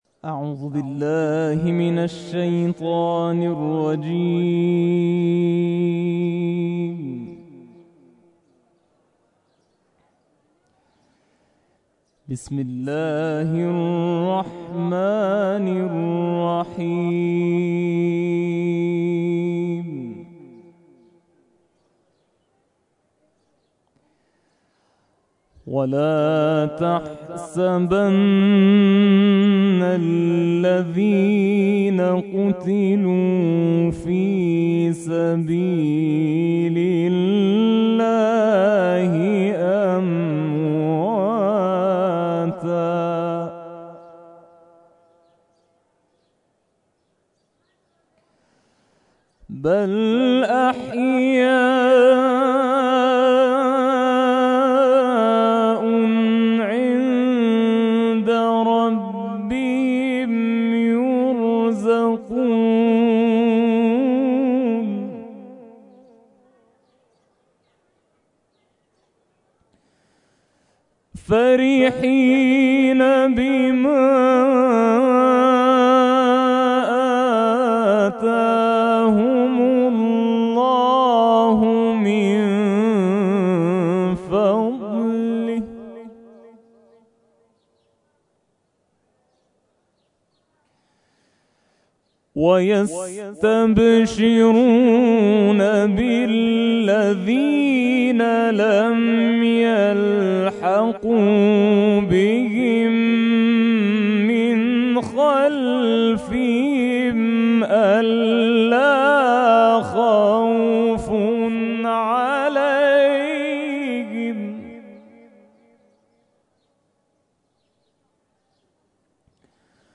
تلاوت
در ادامه تلاوت این قاری ممتاز در یادمان شهدای شلمچه ارائه می‌شود.